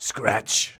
SCRATCH.wav